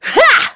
Aselica-Vox_Attack1.wav